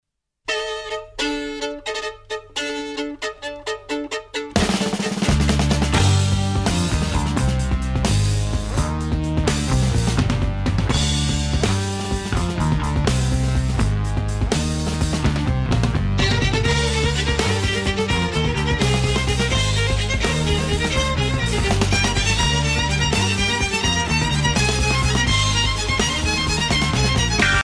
Recorded at Command Studios, London